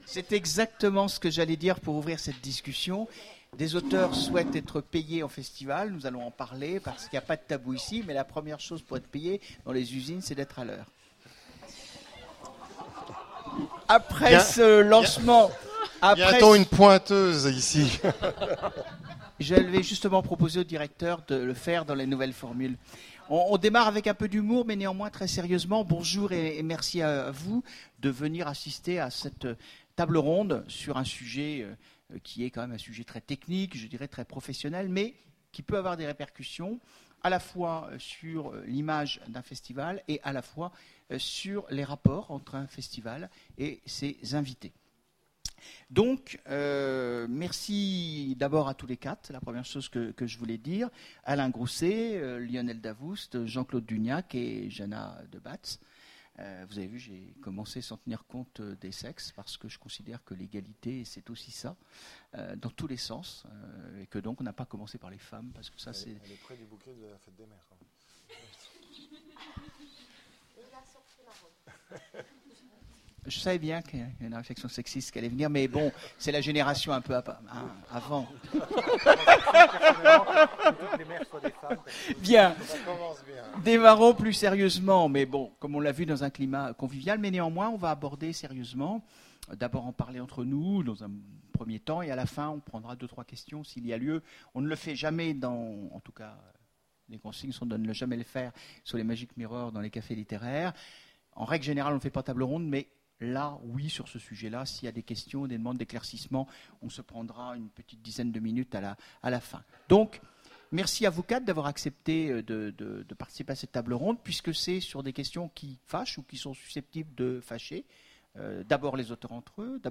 Imaginales 2015 : Conférence Rémunérer les auteurs en festival